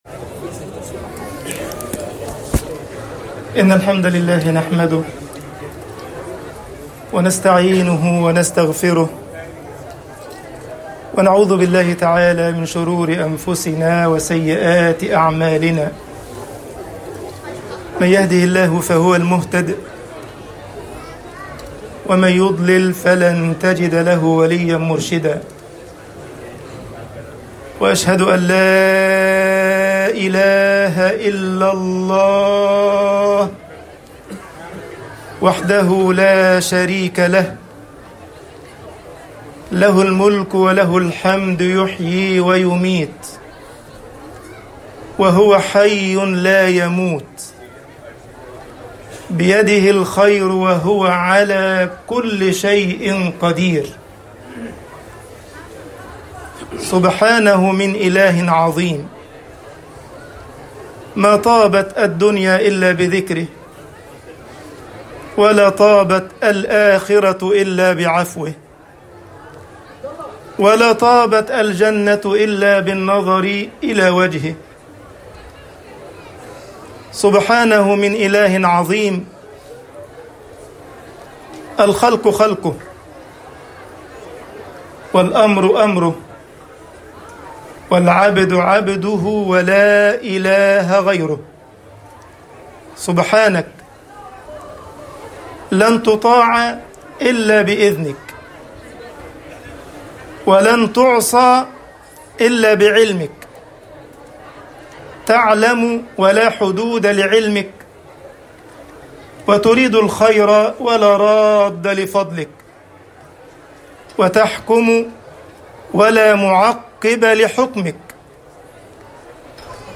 خُطْبَةُ عِيد الْأضْحَى 1445هـ